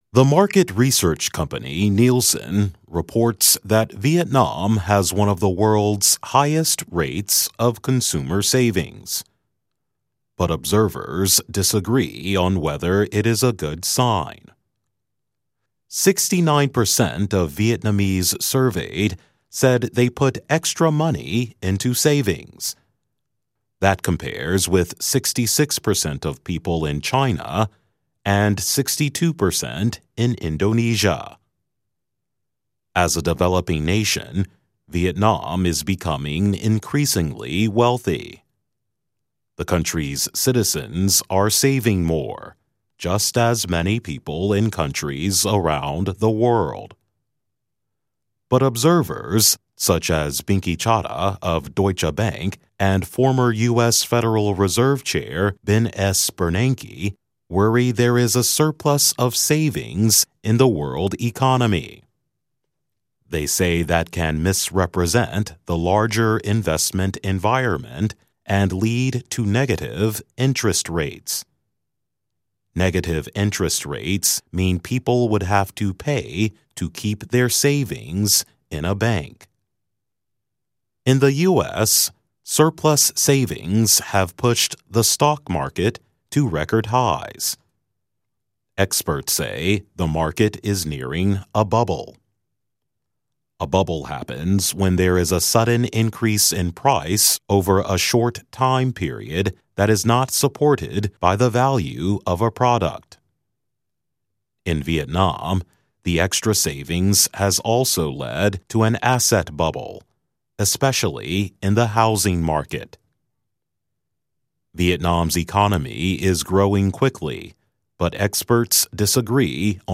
VOA慢速英语 - 越南成为全球储蓄率最高的国际之一
VOA慢速英语, Economics Report, 越南成为全球储蓄率最高的国际之一